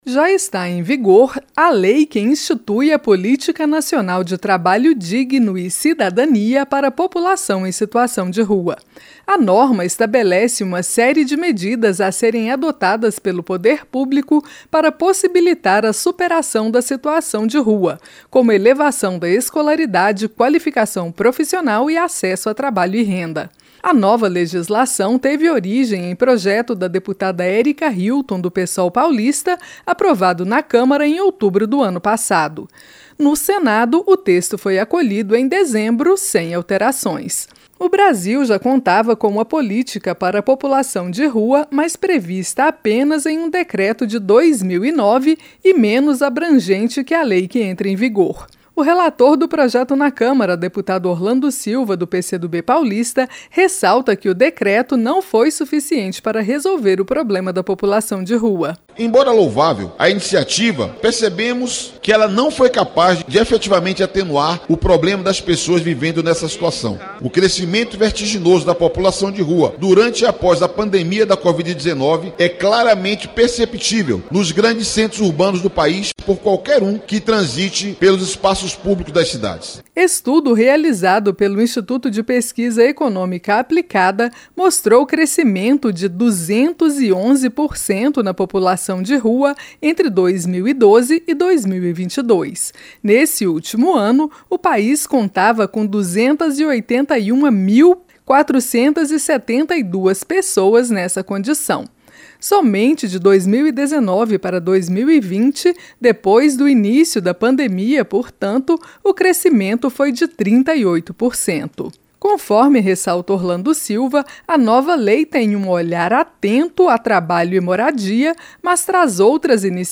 SANCIONADA LEI QUE CRIA POLÍTICA DE TRABALHO DIGNO PARA POPULAÇÃO DE RUA. A REPÓRTER